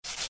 擦东西.mp3